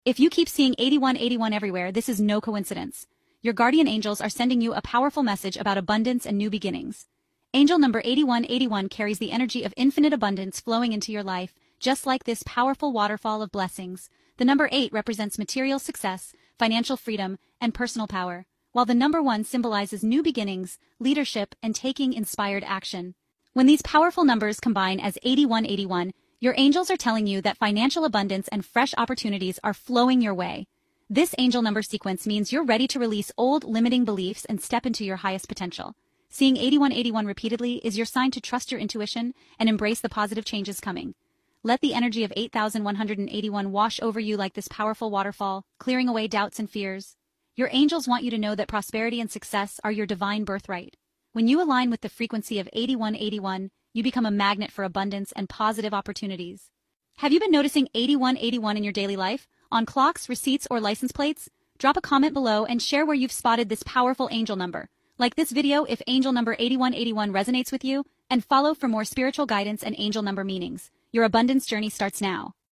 This 90-second spiritual guide explores 8181 meaning, 8181 angel number messages, and how the 8181 manifestation energy aligns with the law of attraction. Paired with calming waterfall visuals and a clear American female narration (voice at 1.2x), this short video reveals practical steps to unlock abundance, confidence, and financial flow.